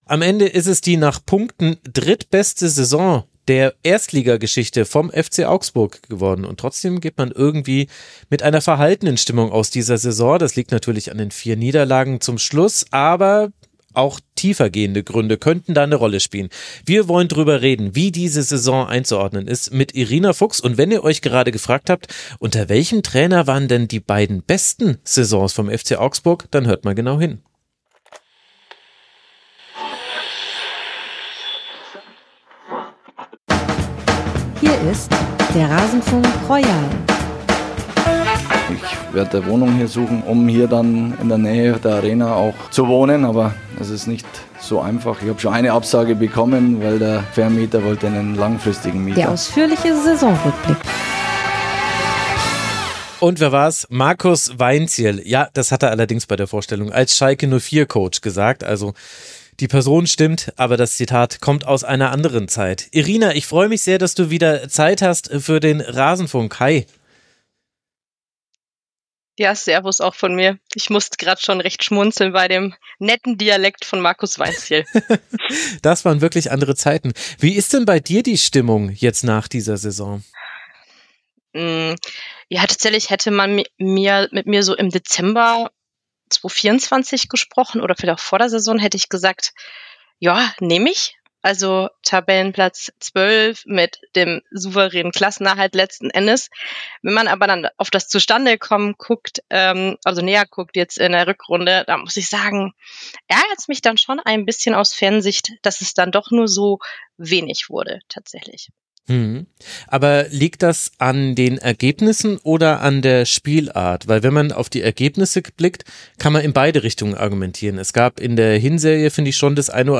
Zu unseren Gästen zählen Journalist*innen, Fans, Analyst*innen und Kommentator*innen.